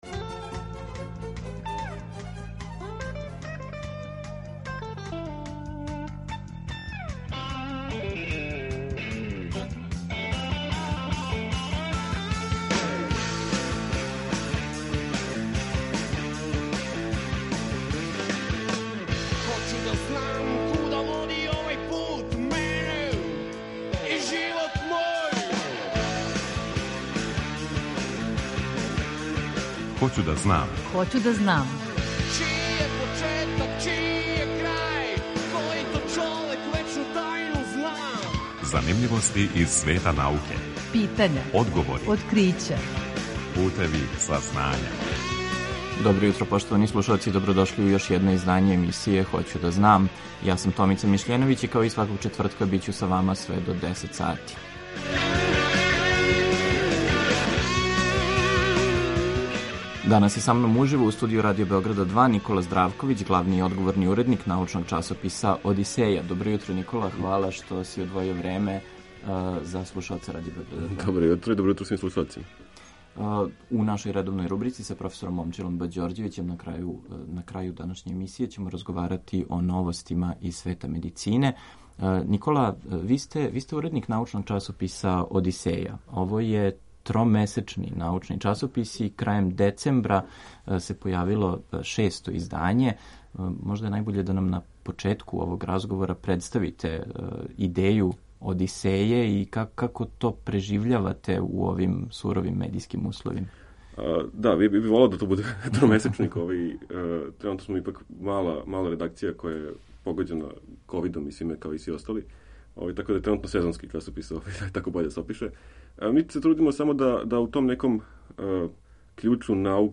Извор: Радио Београд 2
Аудио подкаст